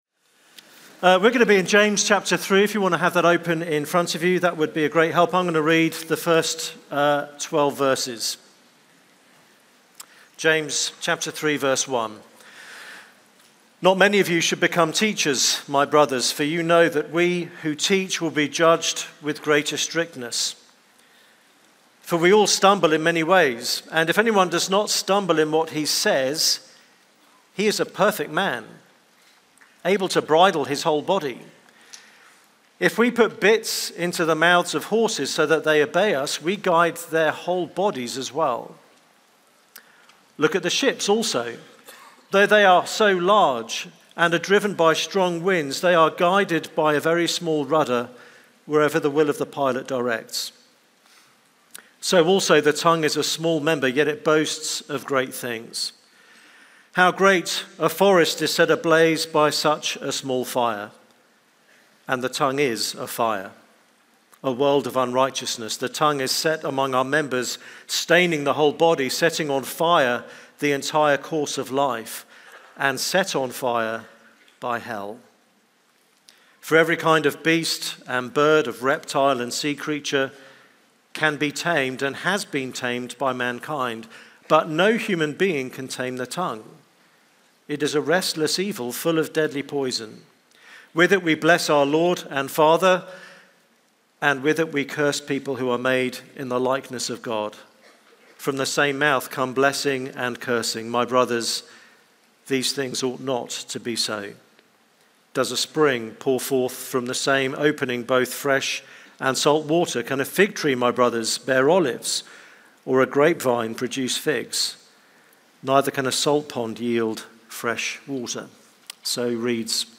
Cedarville University Chapel Message